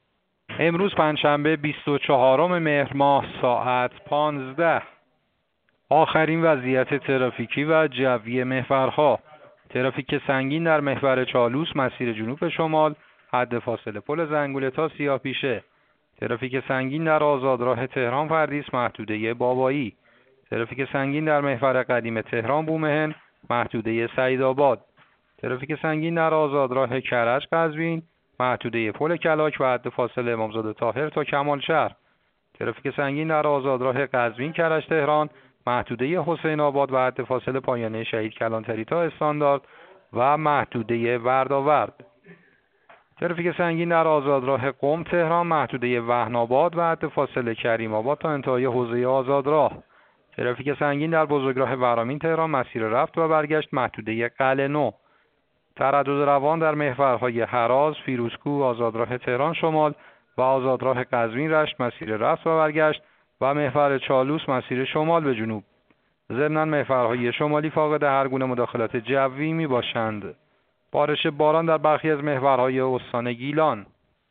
گزارش رادیو اینترنتی از آخرین وضعیت ترافیکی جاده‌ها ساعت ۱۵ بیست‌وچهارم مهر؛